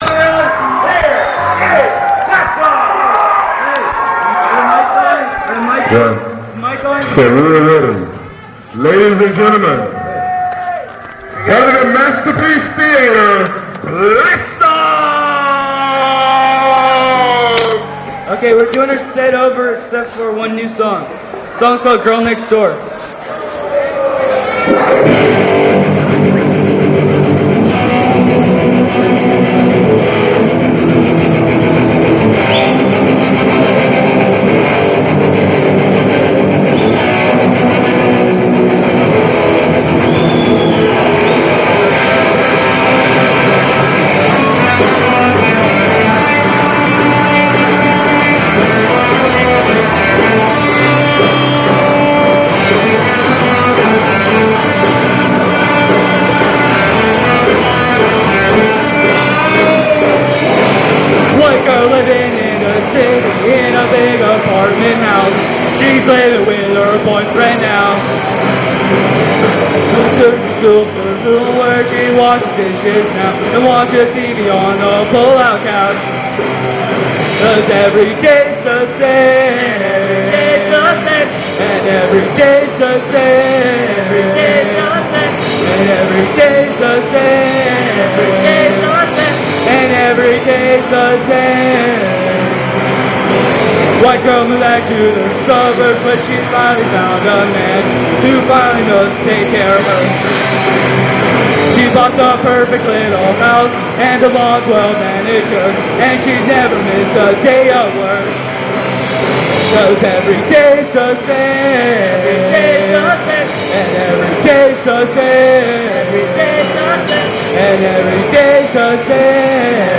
punk rock band
How funny!